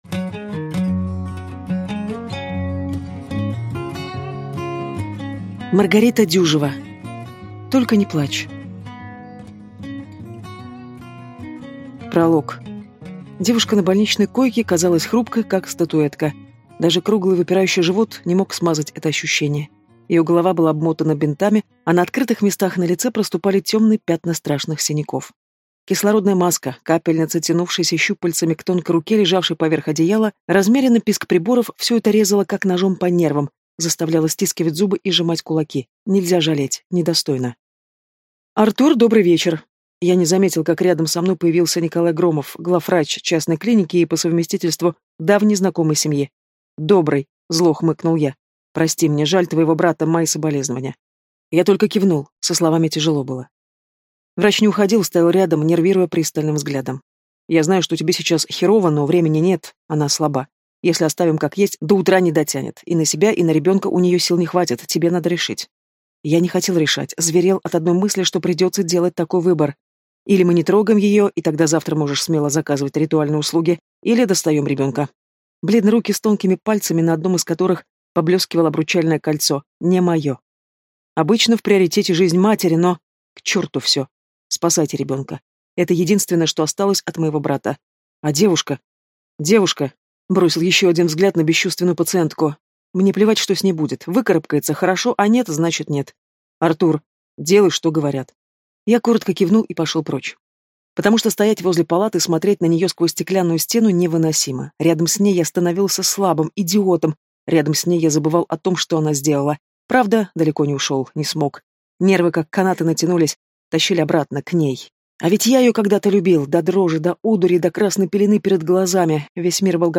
Аудиокнига Только не плачь | Библиотека аудиокниг